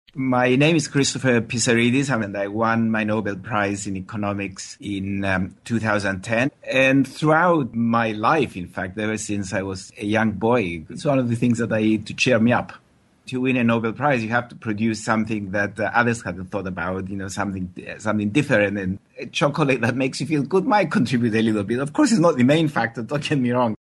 【英音模仿秀】你所不知的巧克力神效 听力文件下载—在线英语听力室